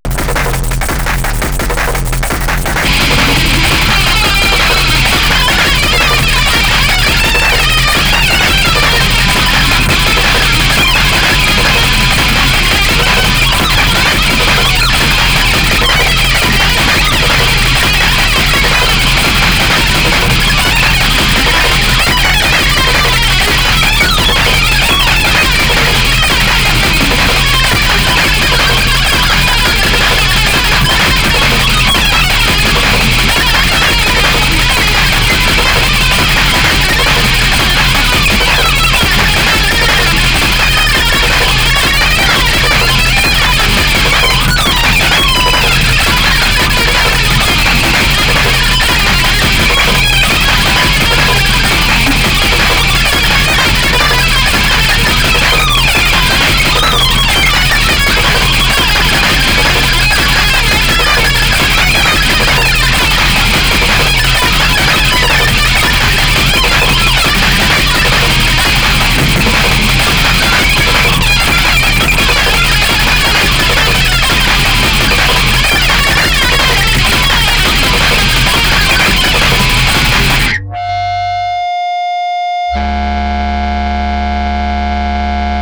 ファン待望の、ハードコア・テクノとハードコア・インプロヴィゼーションが融合した、
圧倒的な高エネルギー、高密度、高速度、高圧力、高温度、高濃度、高純度、高硬度、高光度な